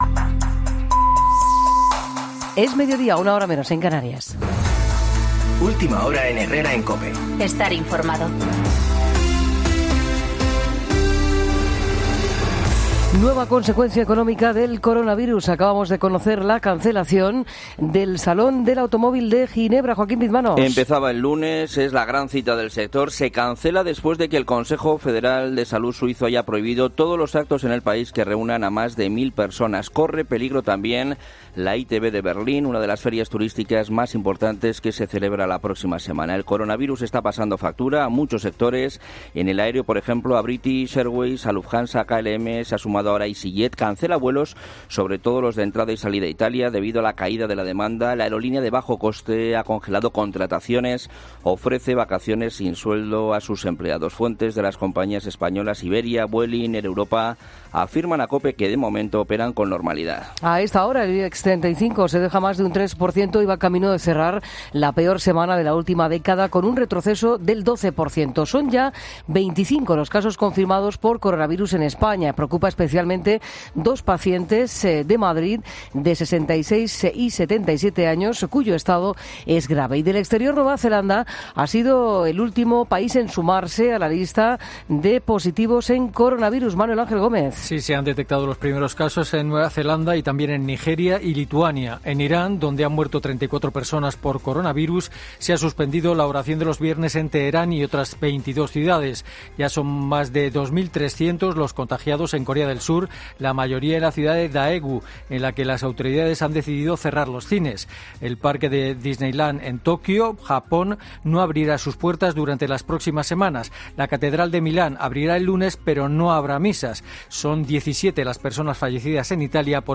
Boletín de noticias COPE del 28 de febrero a las 12.00 horas